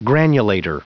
Prononciation du mot granulator en anglais (fichier audio)
Prononciation du mot : granulator